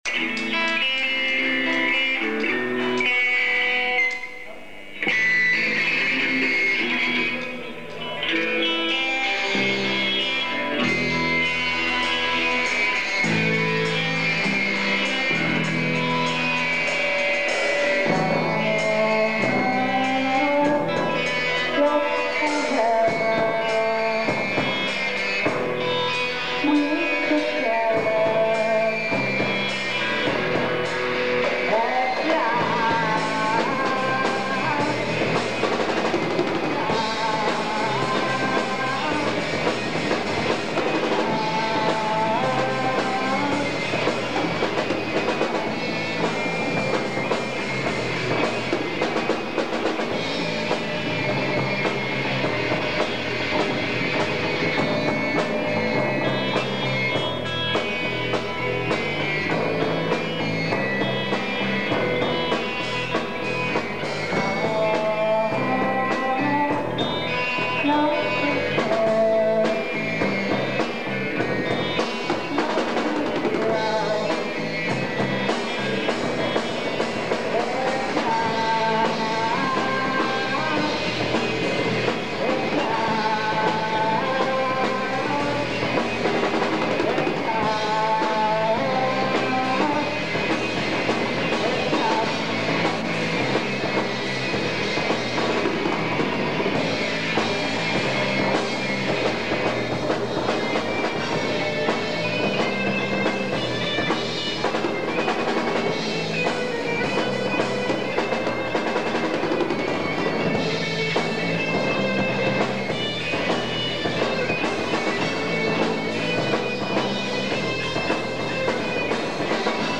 КОНЦЕРТ В КИНОТЕАТРЕ "ПИОНЕР"
электрическая гитара
ударные.